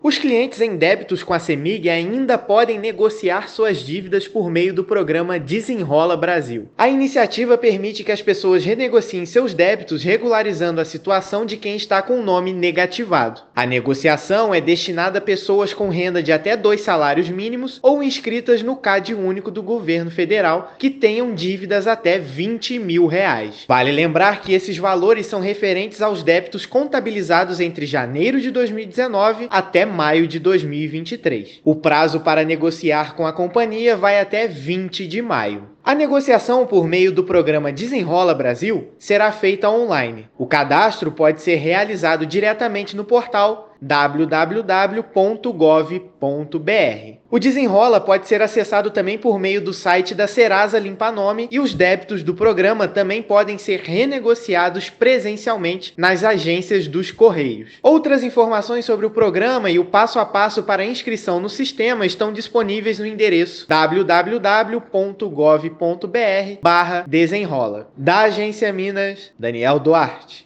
Consumidores podem aproveitar as condições especiais para ficar em dia com a Companhia. Ouça matéria de rádio.